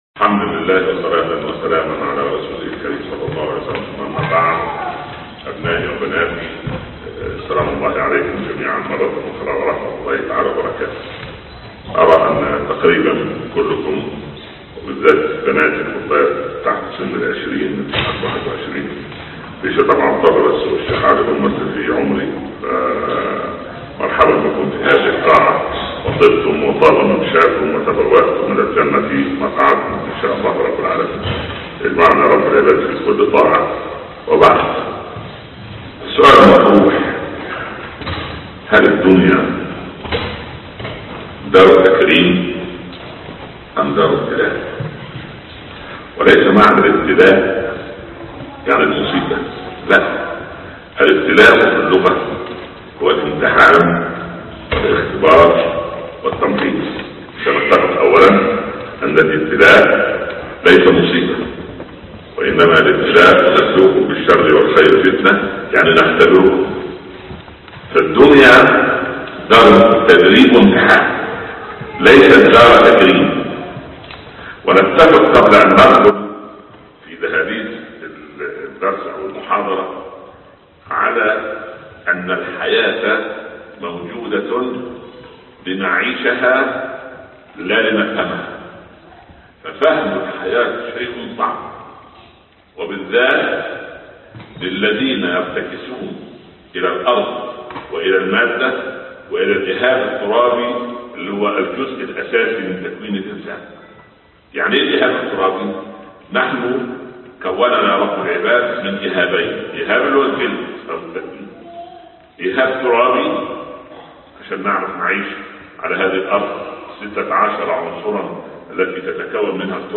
الابتلاء بين دفع و رفع - خطب الشيخ بالسويد - الشيخ عمر بن عبدالكافي